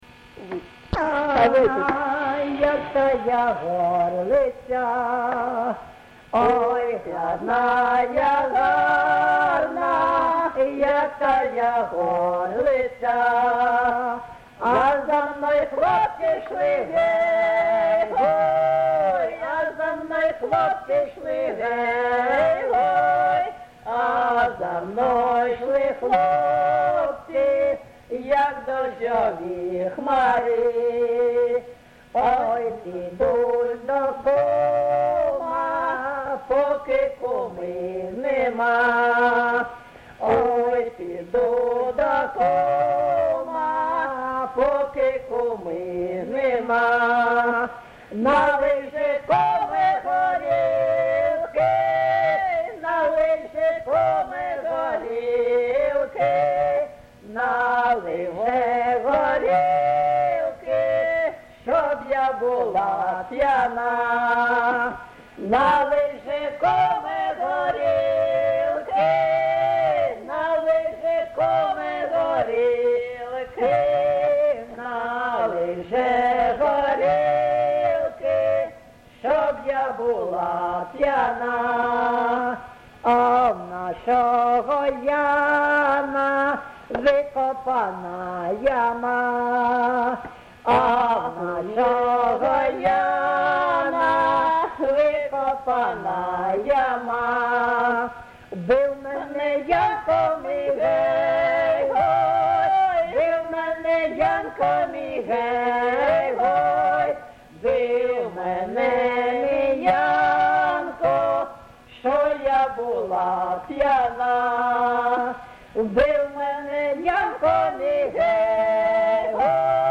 ЖанрПісні з особистого та родинного життя, Жартівливі
Місце записум. Антрацит, Ровеньківський район, Луганська обл., Україна, Слобожанщина